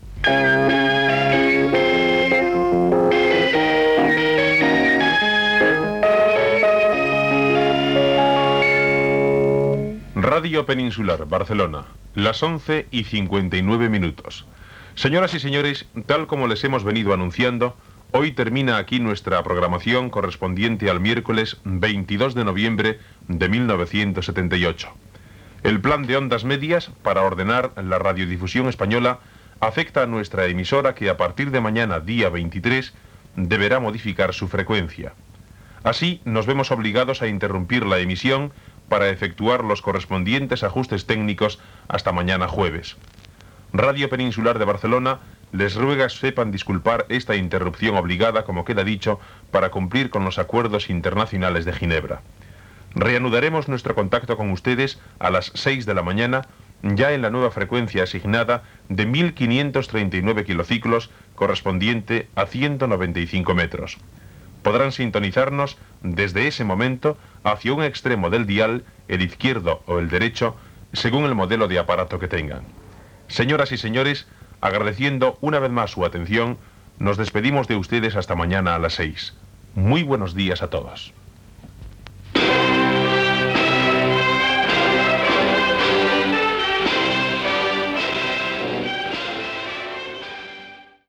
Sintonia, avís del canvi de freqüència i tancament d'emissió amb l'himne d'Espanya.